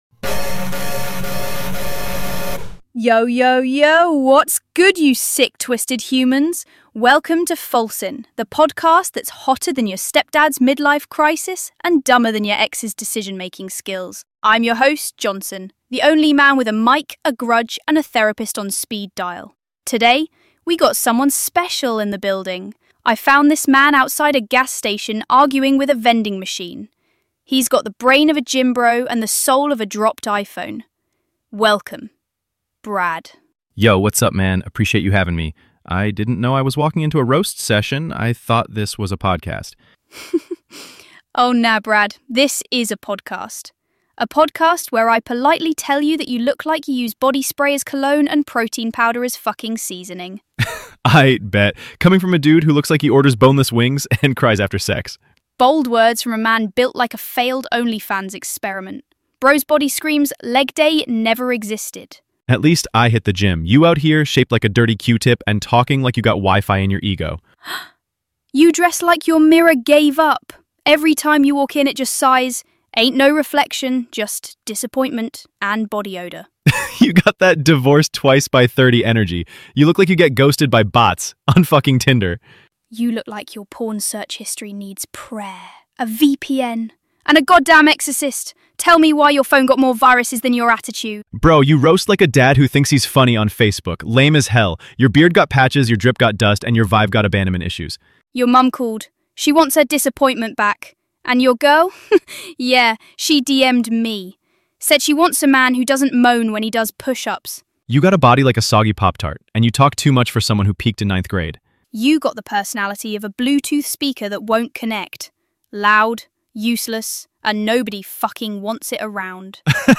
No script. No filters. Just verbal violence and savage truth bombs.